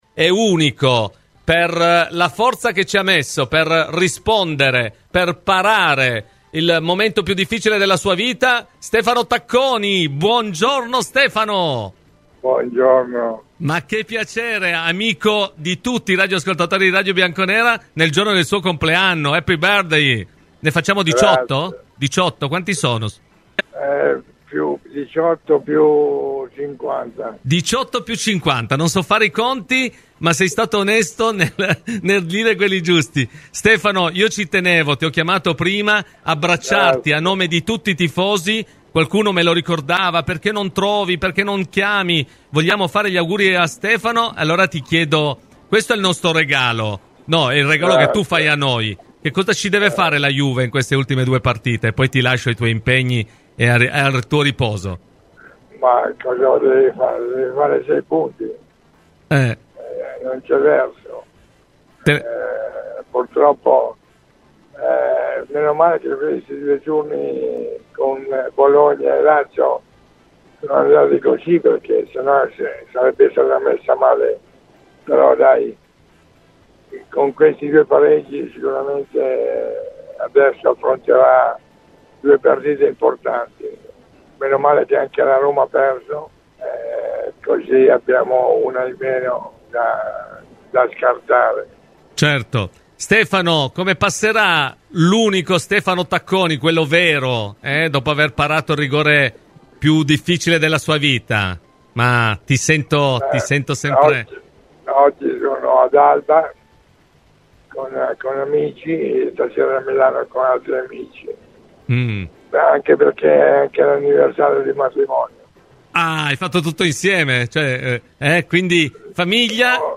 Nel giorno del suo 68mo compleanno, la leggenda bianconera Stefano Tacconi ha voluto fare un regalo ai tifosi della Juventus e a Radio Bianconera, intervenendo nel corso di "Cose di Calcio" per riservare una battuta alla corsa alla prossima Champions: "La Juve adesso deve fare sei punti, all'indomani del doppio scontro diretto si trova in una posizione favorevole, bene o male i pareggi con Bologna e Lazio hanno permesso di arrivare in vista degli ultimi impegni con la possibilità di raggiungere il traguardo vincendo tutte le partite.